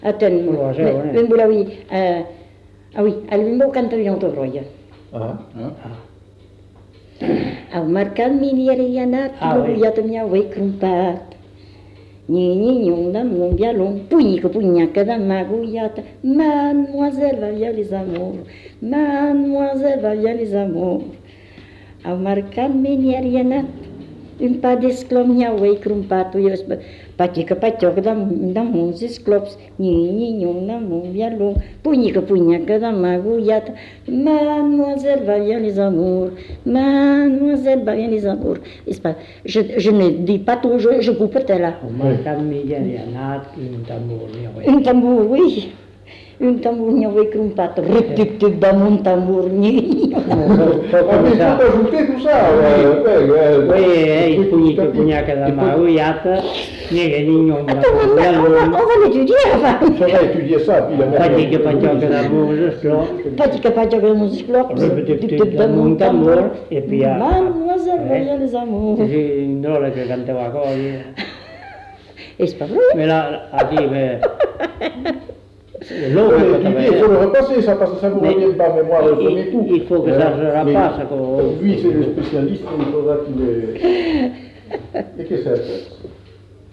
Aire culturelle : Bazadais
Lieu : Cazalis
Genre : chant
Effectif : 2
Type de voix : voix de femme ; voix d'homme
Production du son : chanté
Classification : énumératives diverses